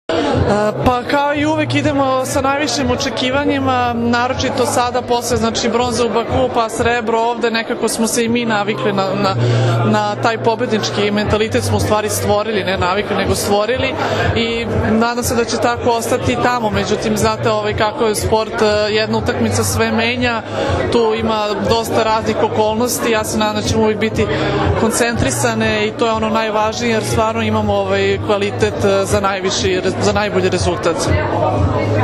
IZJAVA JELENE NIKOLIĆ